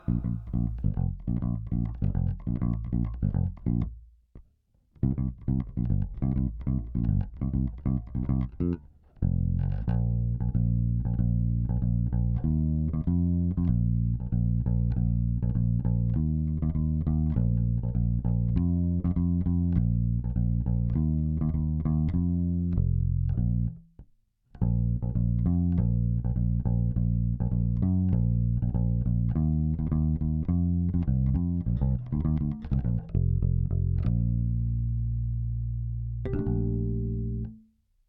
ベースラインでこの曲なーんだ